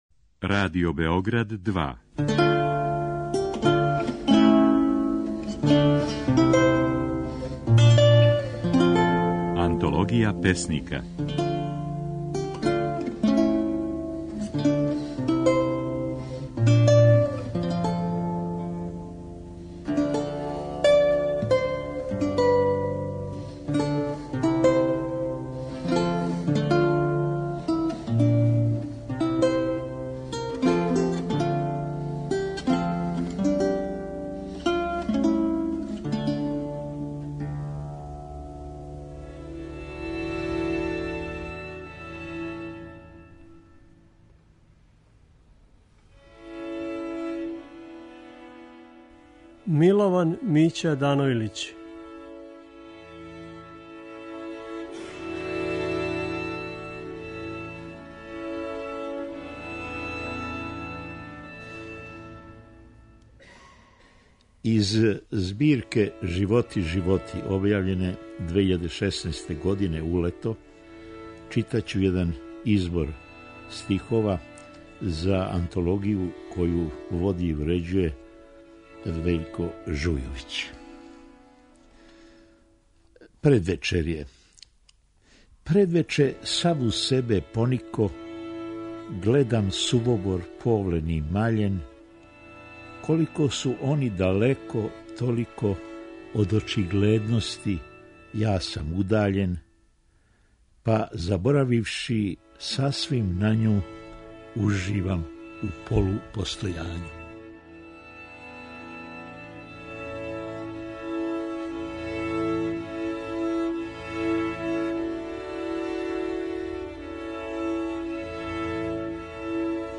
Oд 3. до 7. новембра, можете чути како своје стихове говори Милован Данојлић.